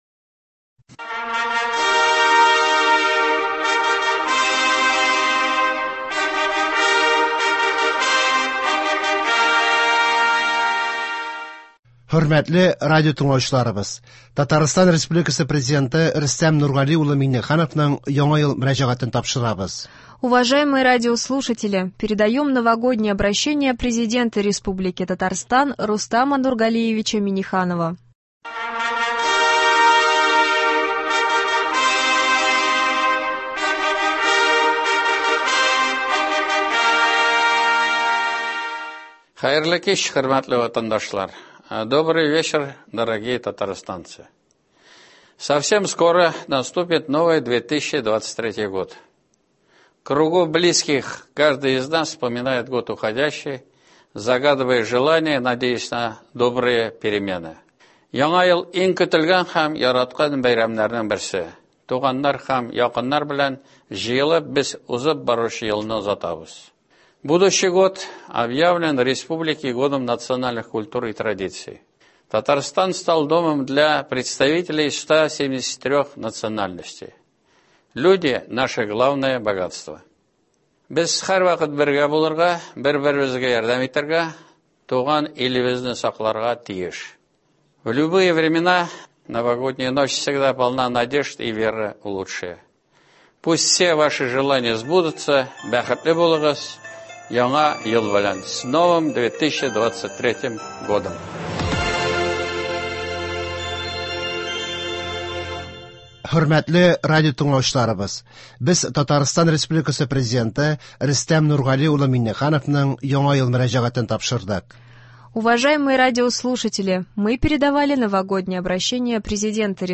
Татарстан Президенты Рөстәм Миңнехановның Яңа ел Мөрәҗәгате.
Новогоднее обращение Президента Татарстана Рустама Минниханова.